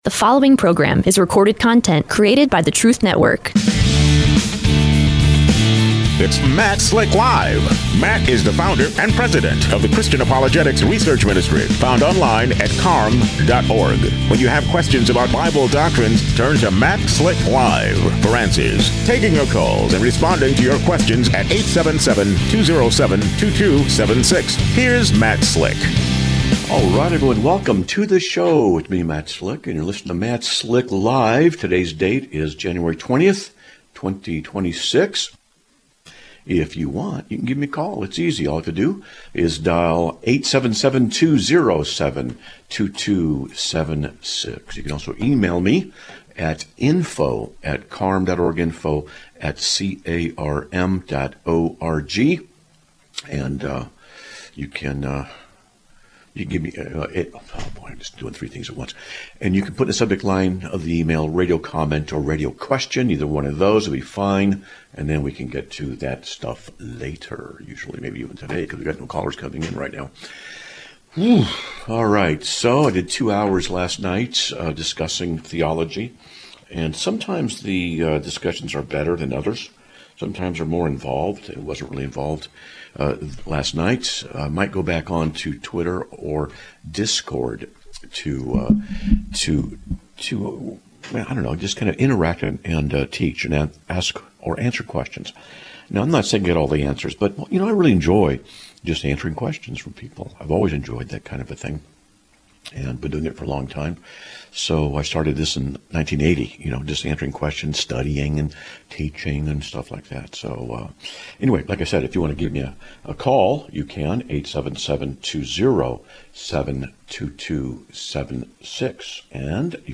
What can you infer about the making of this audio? Live Broadcast of 01/20/2026